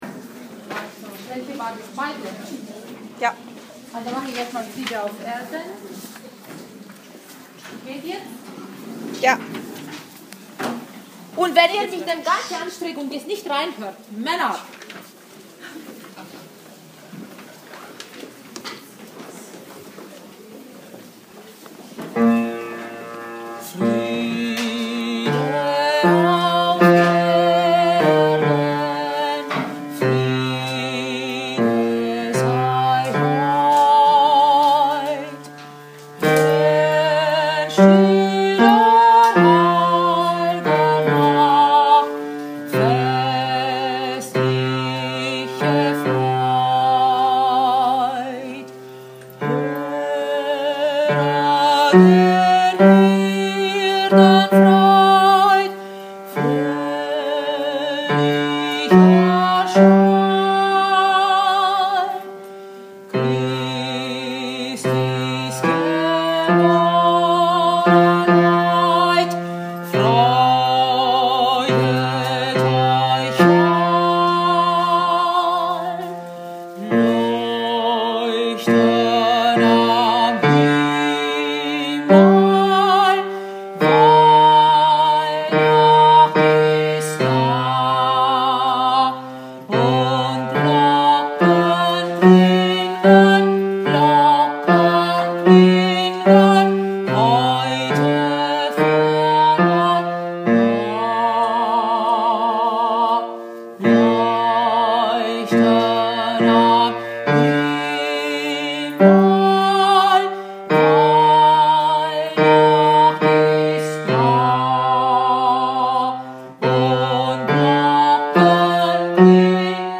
Friede auf Erden / Gefroren hat es… – Bass